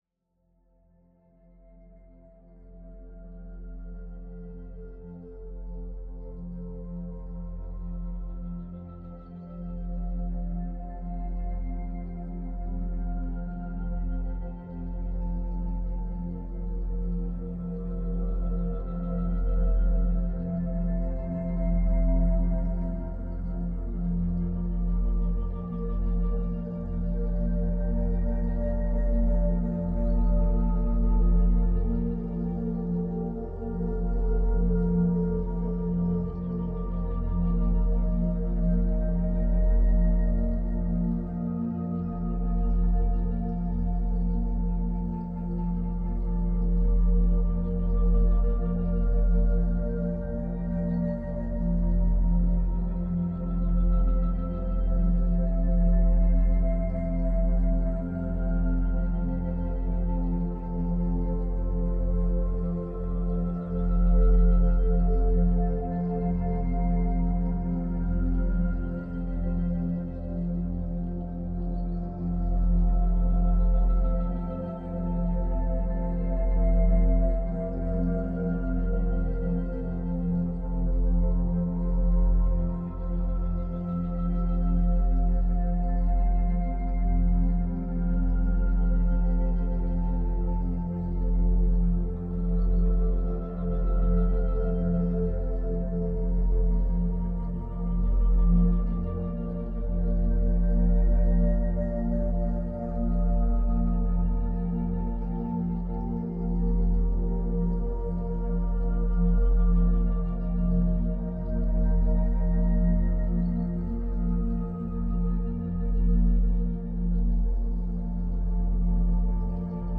174-music-only-22.mp3